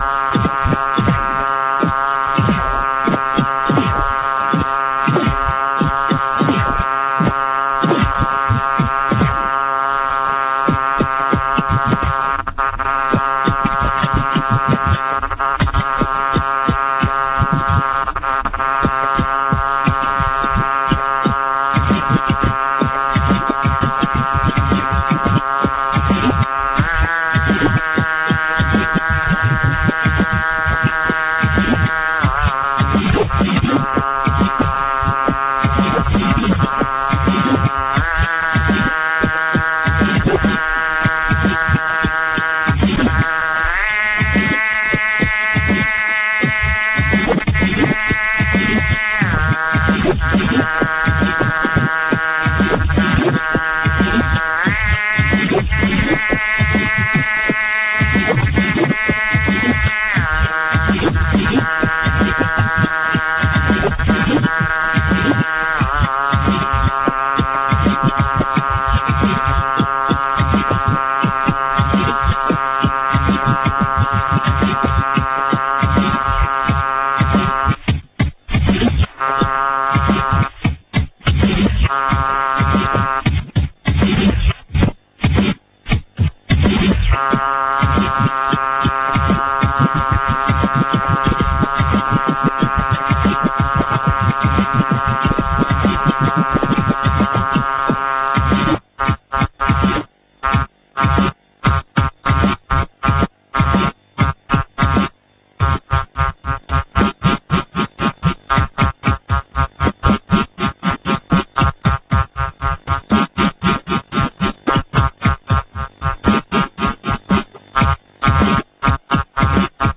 drum and tone
beatandtone1.rm